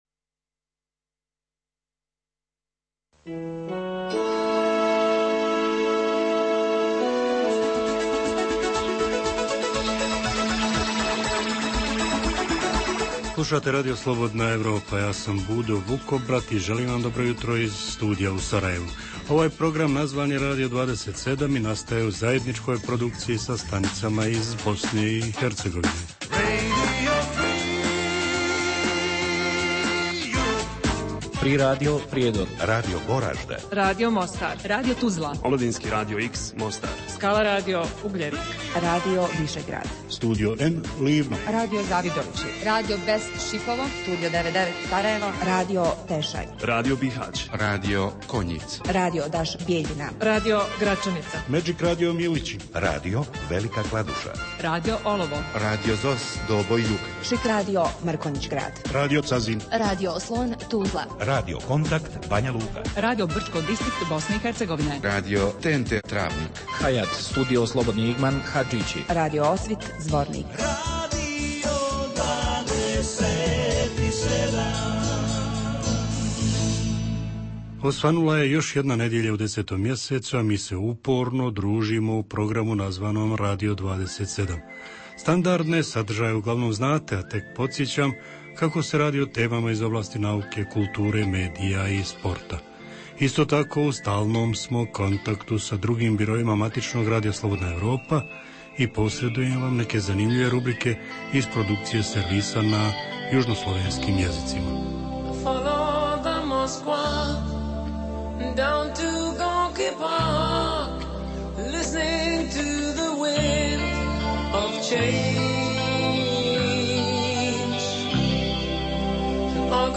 Jutarnji program namijenjen slušaocima u Bosni i Hercegovini. Sadrži intervju, te novosti iz svijeta nauke, medicine, visokih tehnologija, sporta, filma i muzike.